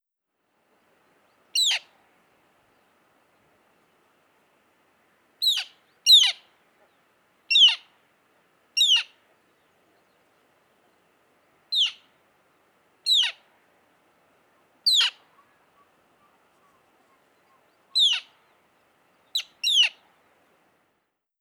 Birds and River
Bird4.wav